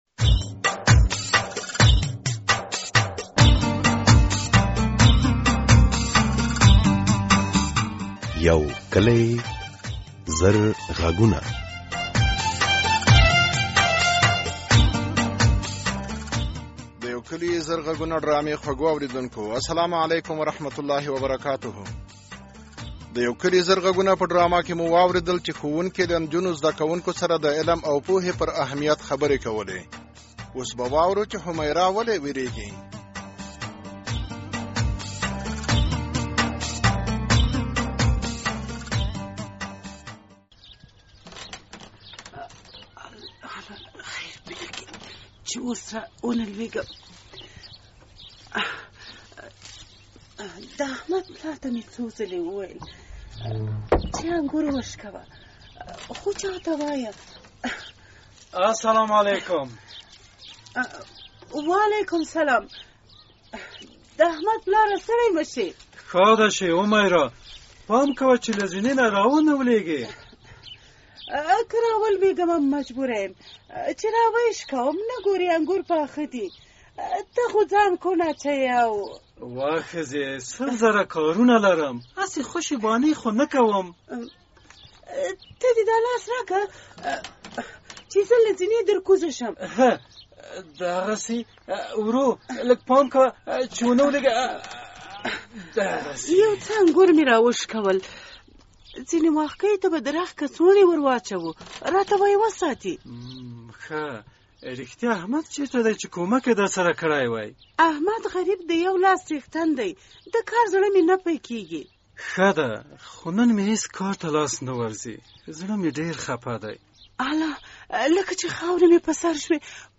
د یو کلي زرغږونو ډرامې په دې برخه کې اوری چې ګلالۍ له واده وروسته هم د خپلو زده کړو د پوره کېدو هیله لري...